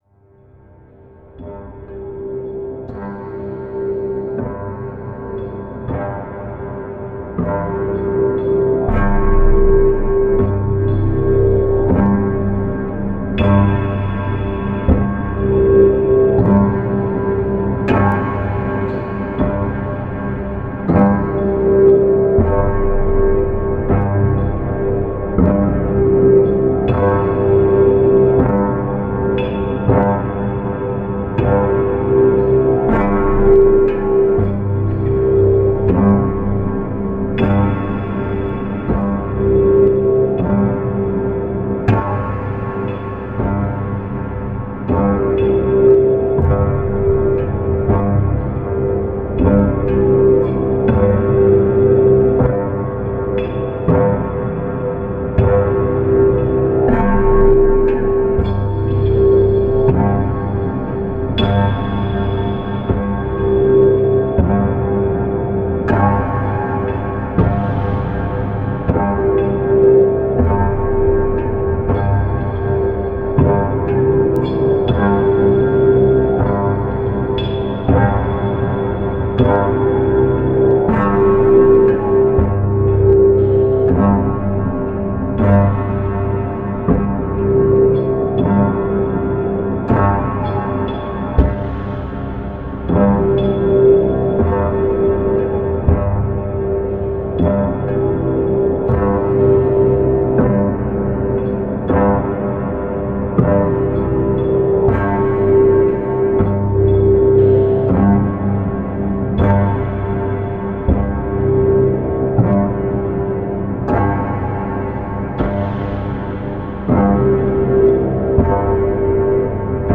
Genre: Industrial.